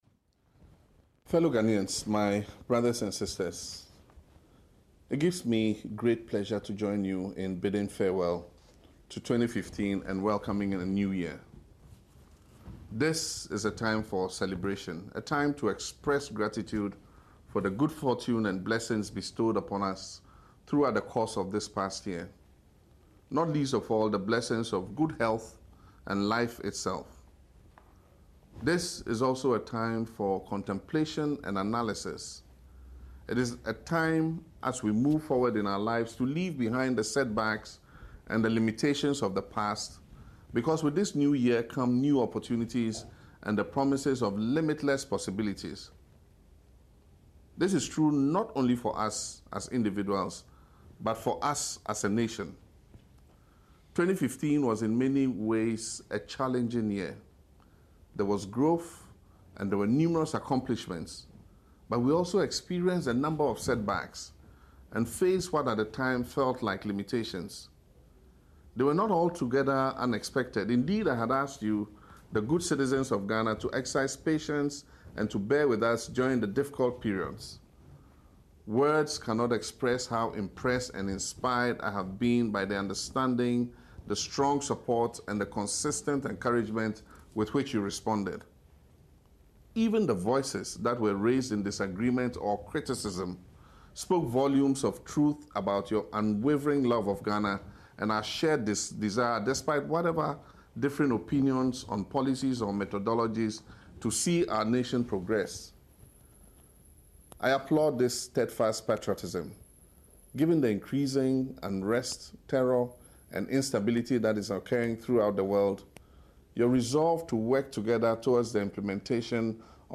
president_mahama_new_year_2016_message.mp3